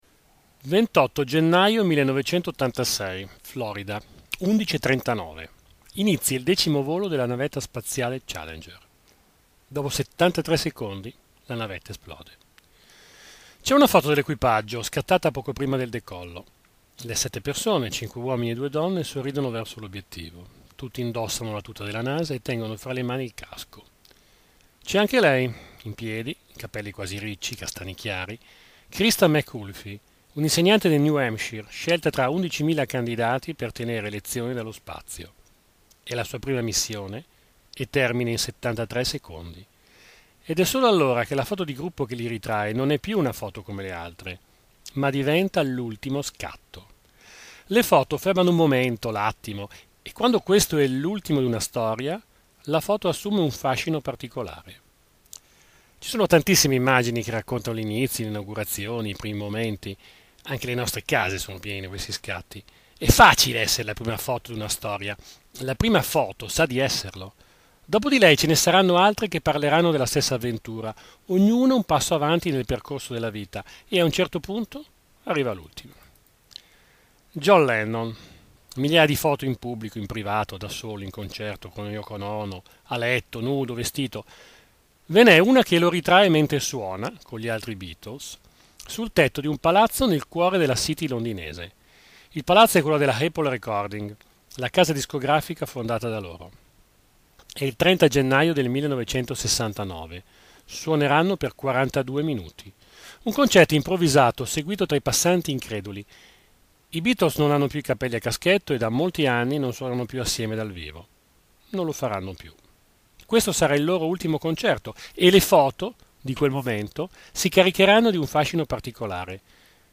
Puntate della rubrica radiofonica l'Angolo di Orso Curioso trasmessa da Web Pieve Radio